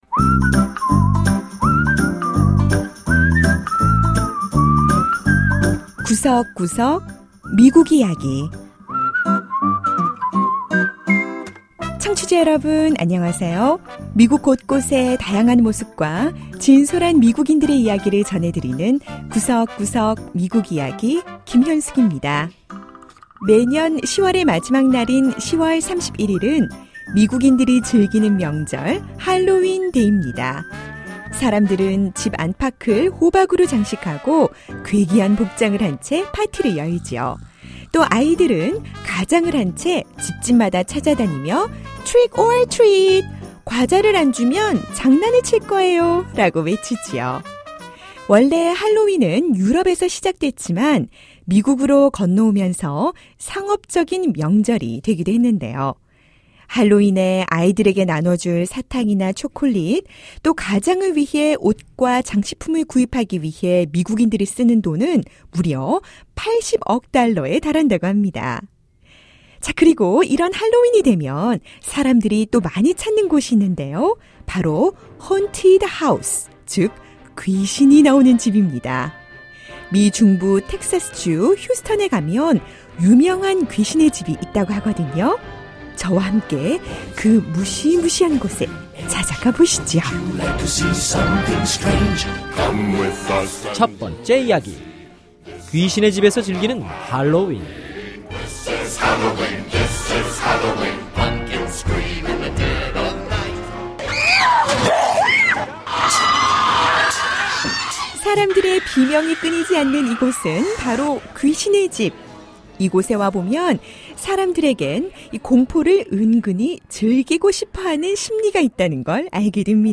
할로윈을 앞두고 가족들의 웃음 소리 가득한 동부 메릴랜드주의 한 호박 농장을 찾아가 봅니다.